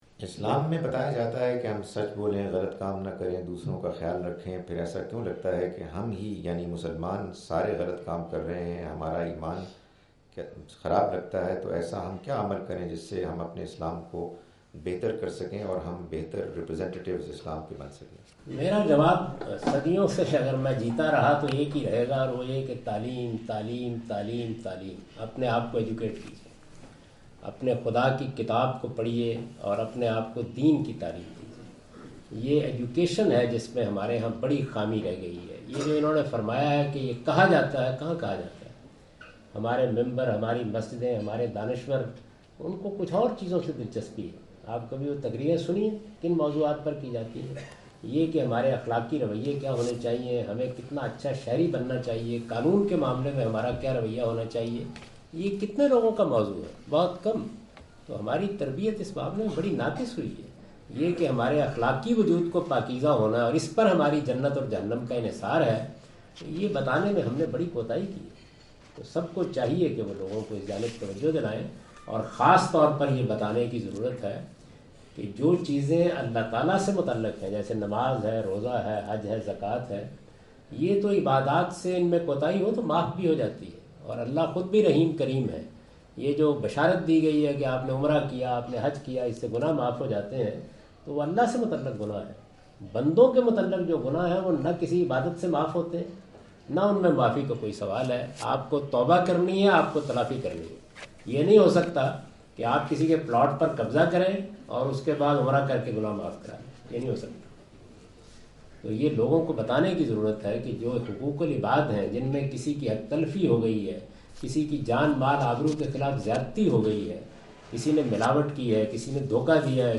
Javed Ahmad Ghamidi answer the question about "the basic reason behind fall of Muslims" during his visit to Manchester UK in March 06, 2016.
جاوید احمد صاحب غامدی اپنے دورہ برطانیہ 2016 کے دوران مانچسٹر میں "مسلمانوں کے زوال کی وجوہات" سے متعلق ایک سوال کا جواب دے رہے ہیں۔